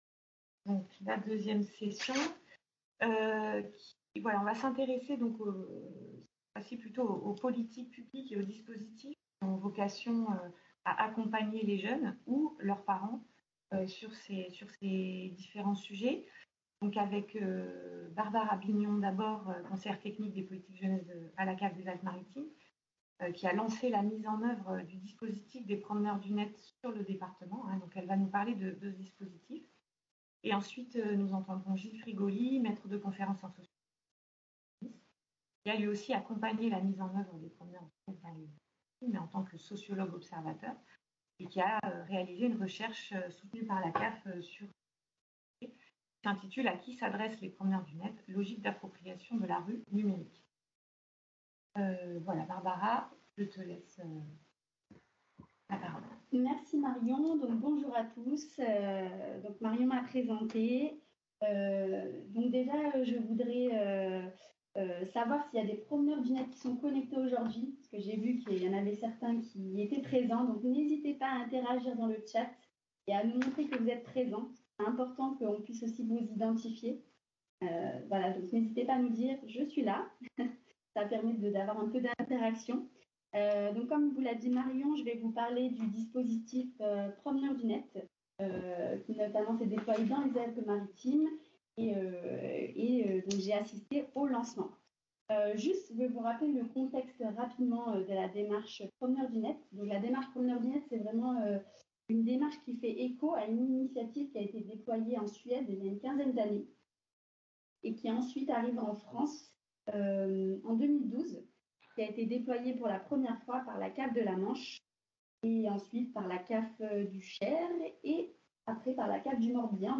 Webconférence, le jeudi 27 mai 2021 de 9h à 12h30, conférence en ligne, rencontre entre chercheur·es et professionnel·les, organisée par l’Urmis et la Caisse d’allocations familiales des Alpes-Maritimes, avec la participation de la Caisse nationale des allocations familiales.